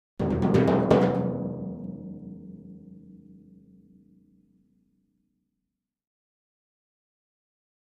Timpani, (Hands), Thin (Pipe) Timpani, Roll Accent, Type 4 - "Timpani Tour